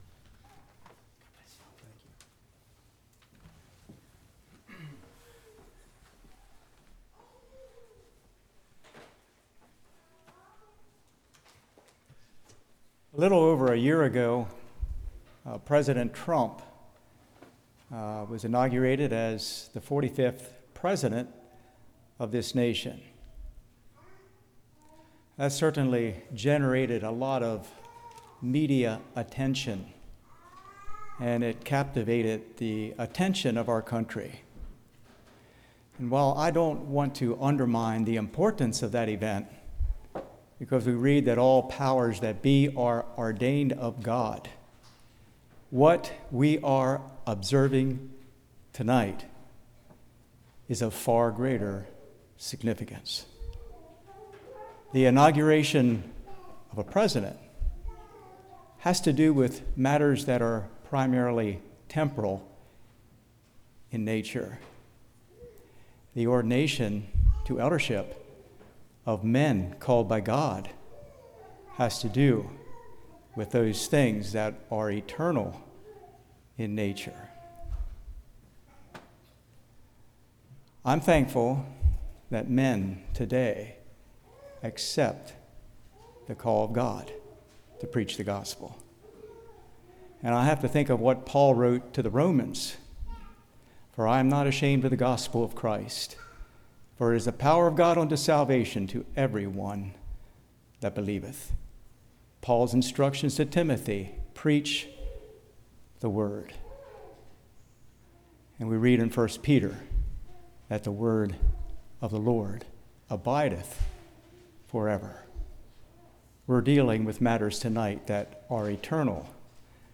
Service Type: Ordination Service